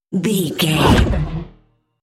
Creature dramatic hit
Sound Effects
Atonal
heavy
intense
dark
aggressive